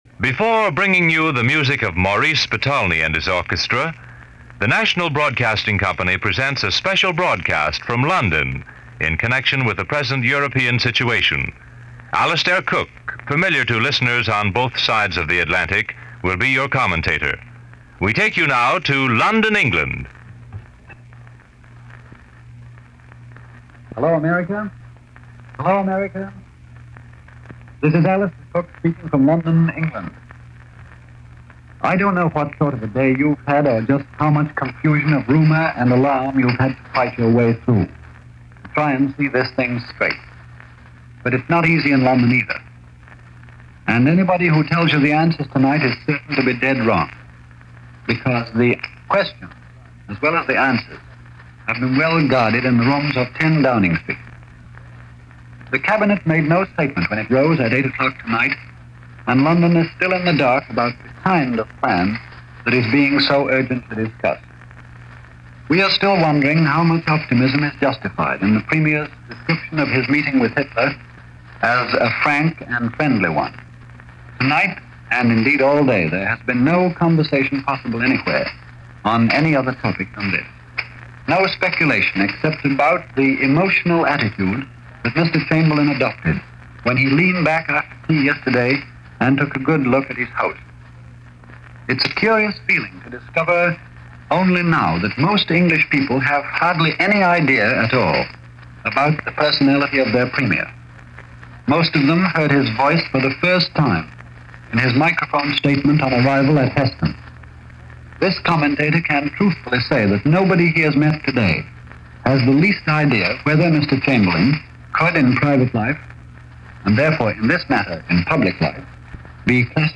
Report from Alistair Cooke – Report of Prime Minister Chamberlain leaving for Munich